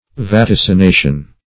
Vaticination \Va*tic`i*na"tion\, n. [L. vaticinatio.]